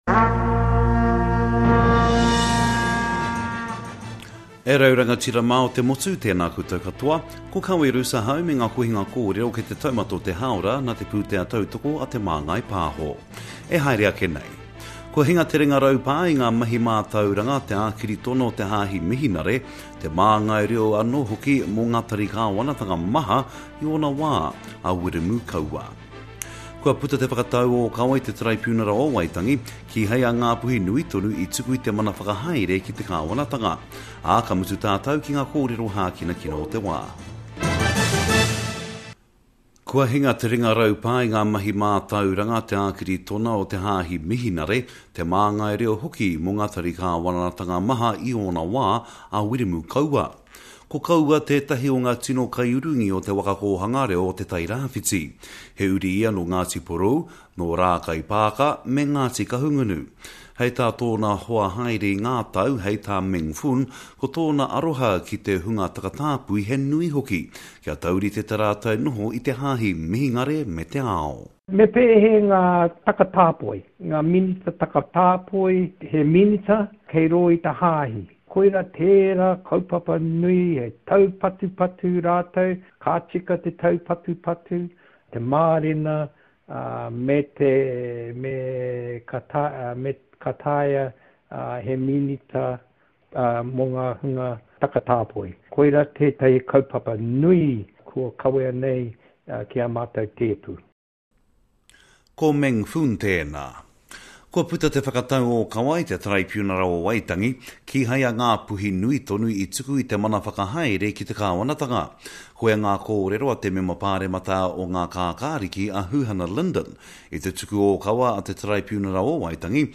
Home » Podcast: Te Reo News